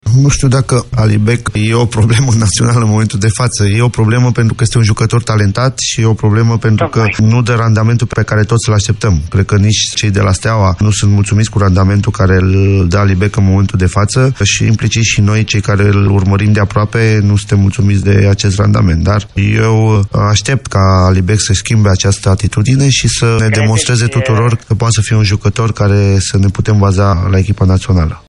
Cunoscut pentru temperamentul lui efervescent, tehnicianul a declarat la Europa FM în emisiunea “Tribuna Zero” că va mai avea răbdare cu doi dintre jucătorii-problemă.